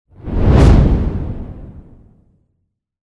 Шум пролетающего огненного шара